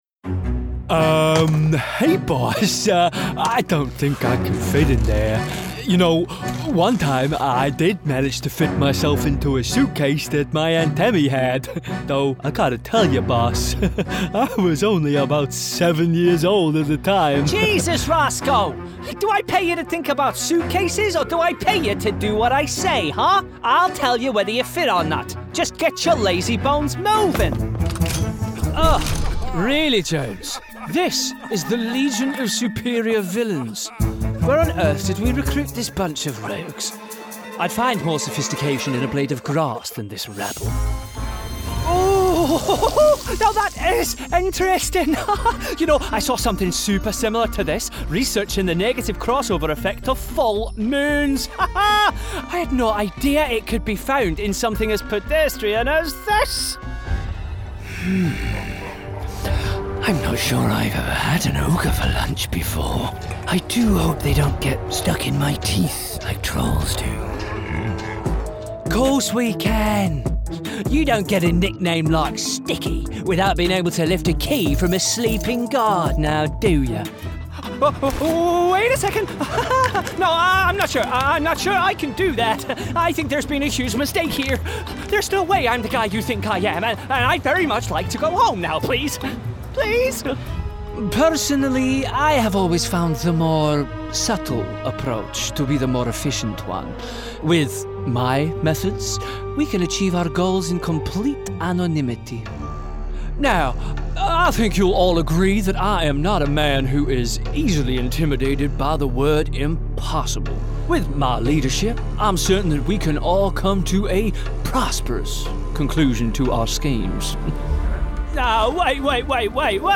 Animation Reel
• Native Accent: Teesside
His versatility shines through as he seamlessly transitions from embodying the relatable everyman to a downtrodden soldier to a sinister villain, delivering each role with care and authenticity.